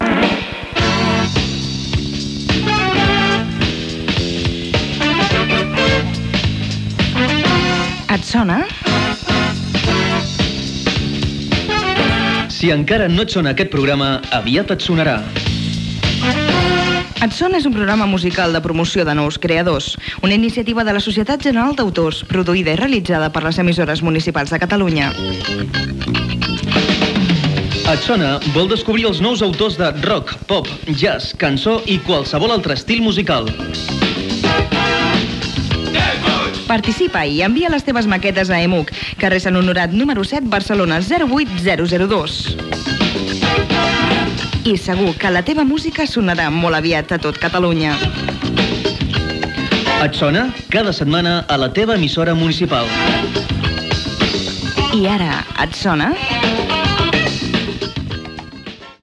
Promoció del programa
Musical